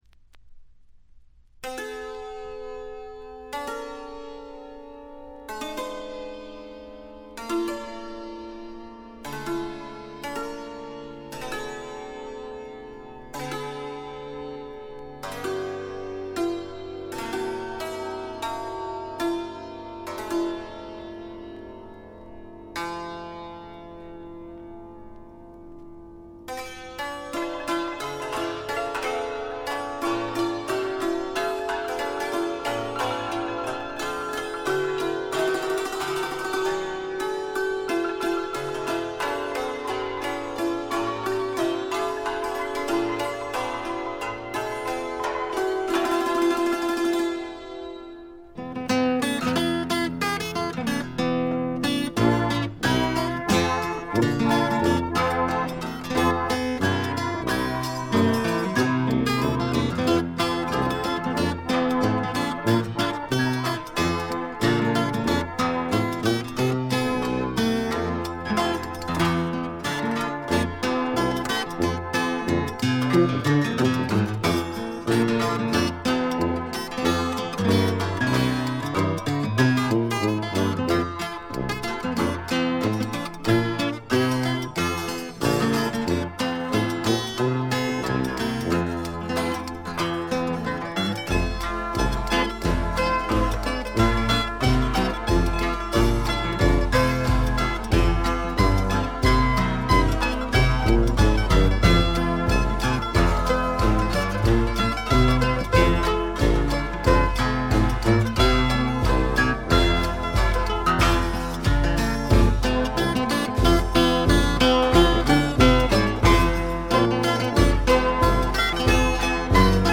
ディスク：部分試聴ですが、静音部での軽微なチリプチ少し。
試聴曲は現品からの取り込み音源です。